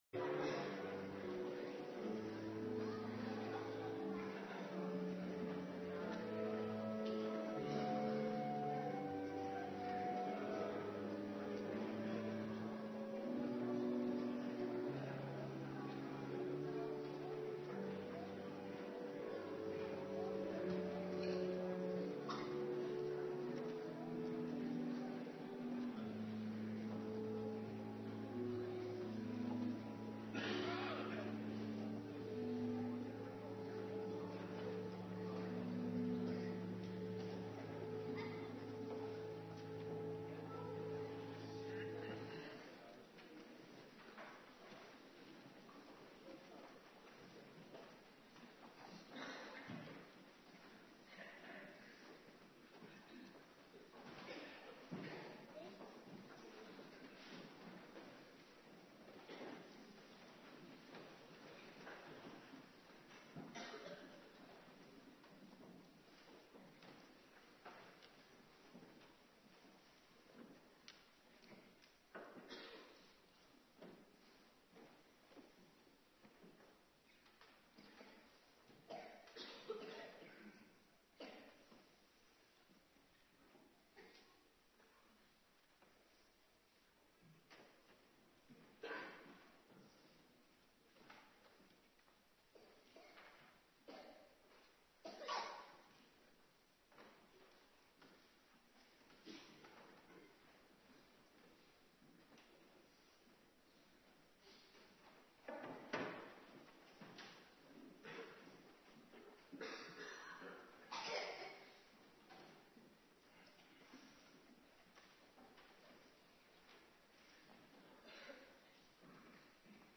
Morgendienst
09:30 t/m 11:00 Locatie: Hervormde Gemeente Waarder Agenda: Kerkdiensten Terugluisteren Numeri 16:1-7, 41-50